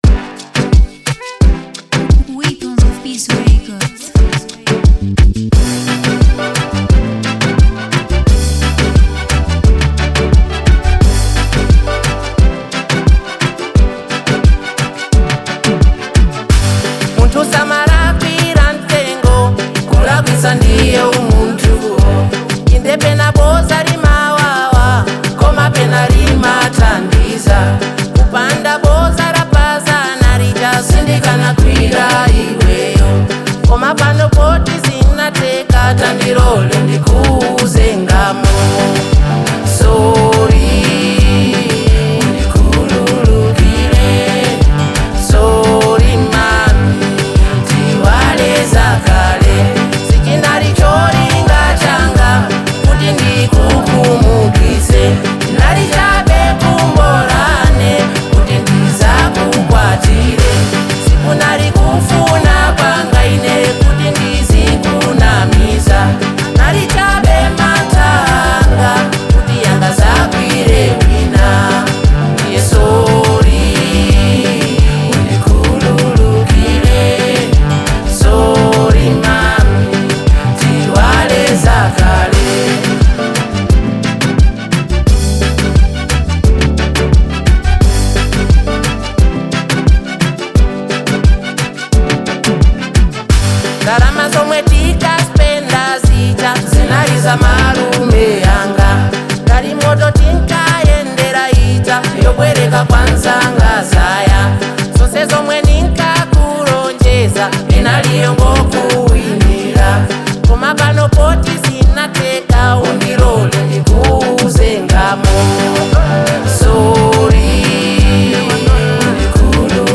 Malawian Afro • 2025-07-18